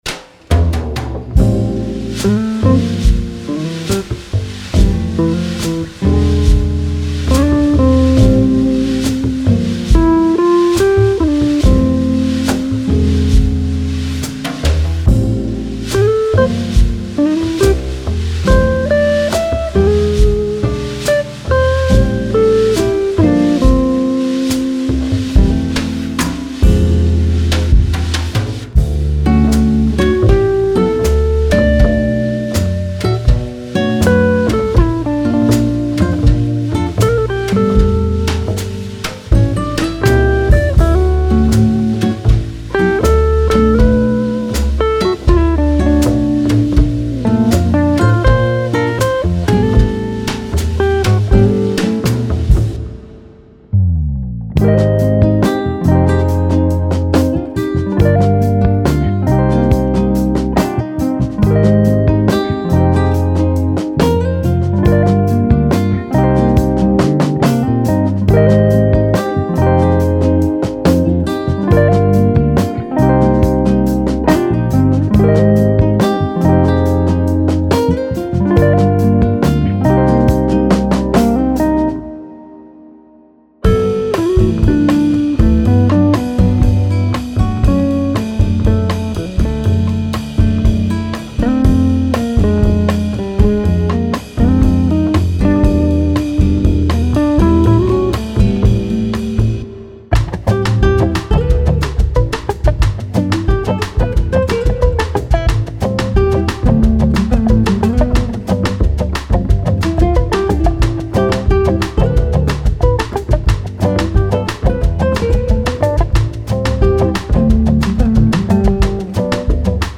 Genre:Jazz
滑らかでソウルフルな雰囲気を持つ高品質なジャズギターループパックを探しているなら、もう探す必要はありません。
このコレクションには、二つの異なるギタースタイルが収録されています。
ナイロン弦のクラシックギター――ステレオマイク構成とアナログプリアンプで収録され、深く自然な響きを実現しています。
リラックスしたコード進行、表現豊かなリフ、滑らかなメロディフレーズなど、無限のインスピレーションが詰まっています。
デモには他のサウンドが含まれていますが、これらはイラスト用であり、本パックには収録されていません。
Tempo/Bpm 70-130